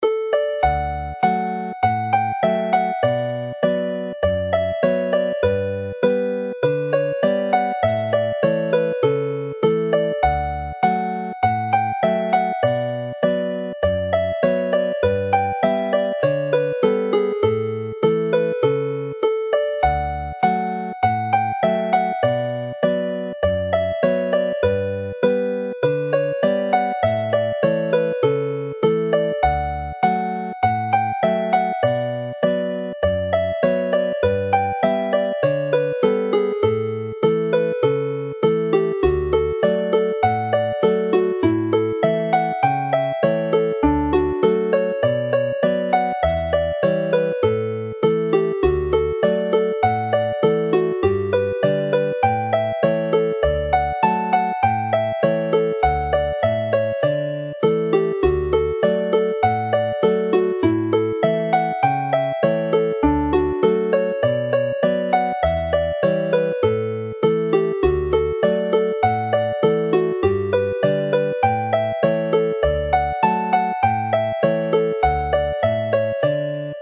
The Dowlais Hornpipe as a reel
Play the tune slowly